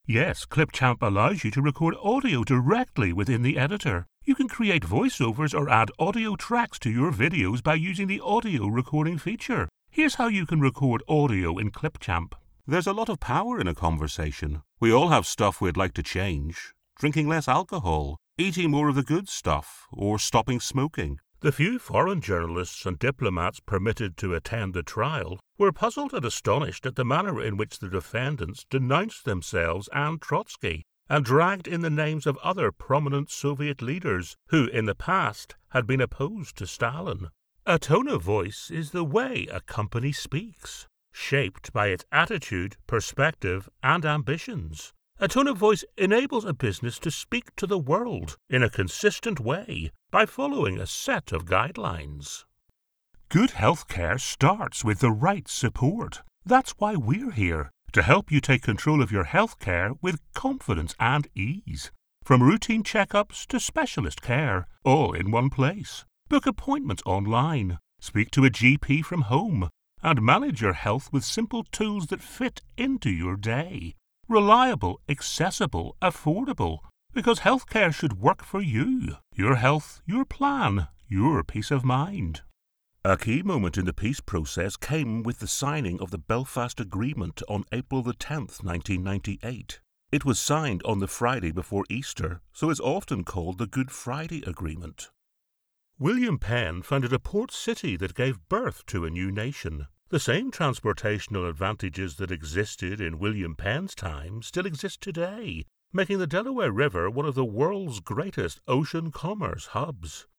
A Northern Irish Voice
Narration Reel
I have a Northern Irish Accent which can be street or upperclass.
I have a deep, versatile, powerful voice, thoughtful , authoritative , storytelling and funny .